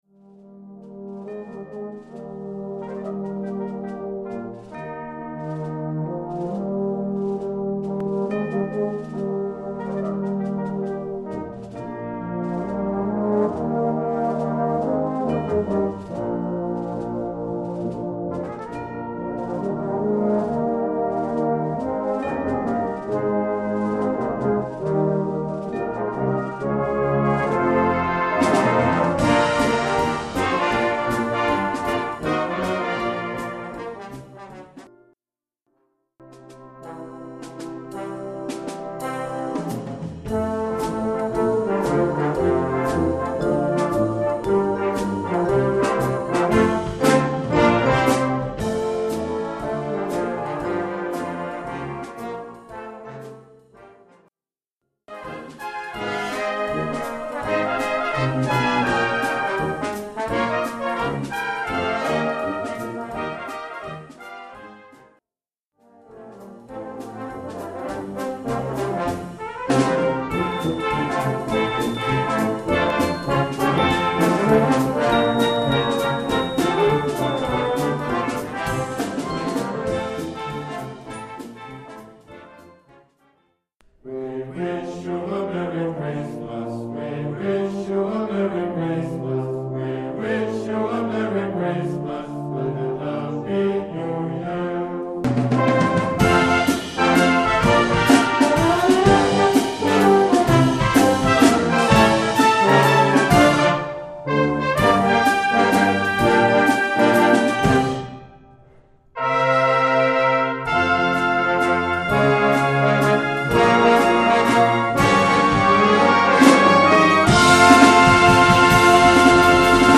Pot-pourri d’airs de Noël suivants:
Wind Band (harmonie)
Christmas Music / Musique de Noël